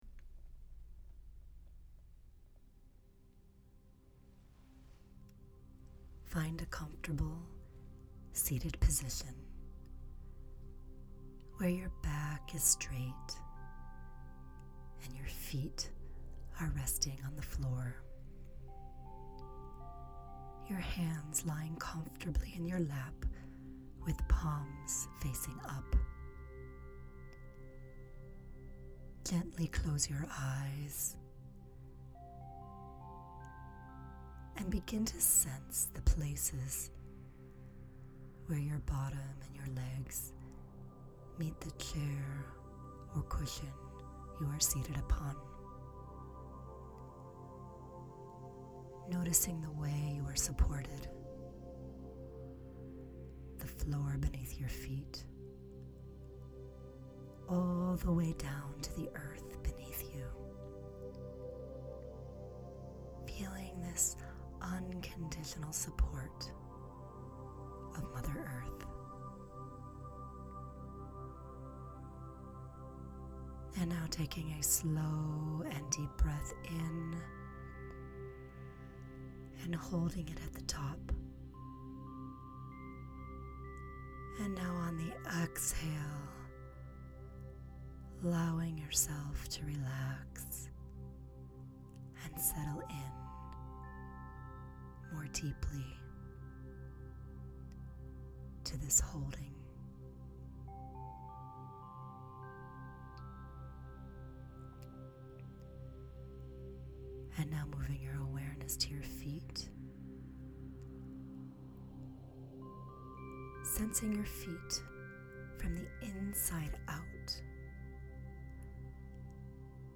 Centering Meditation